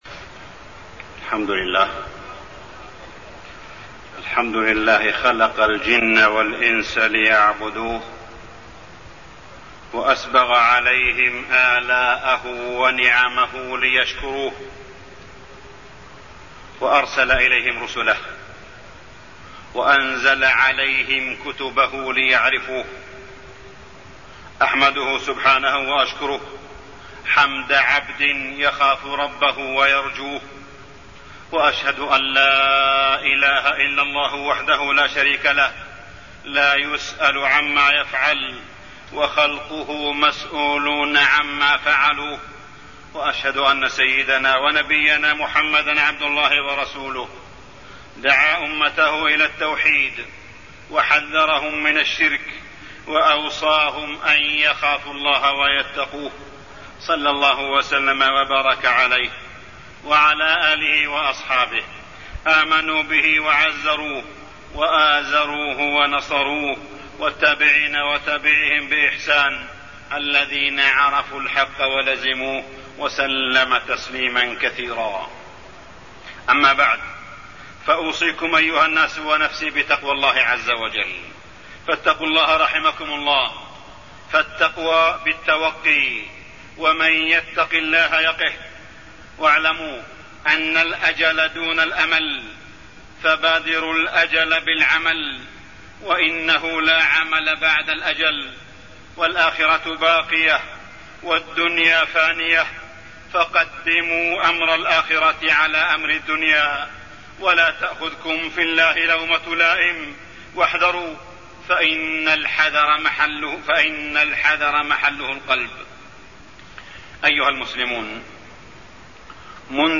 تاريخ النشر ١٦ رجب ١٤٢١ هـ المكان: المسجد الحرام الشيخ: معالي الشيخ أ.د. صالح بن عبدالله بن حميد معالي الشيخ أ.د. صالح بن عبدالله بن حميد الرباط المقدس بين الكعبة والمسجد الأقصى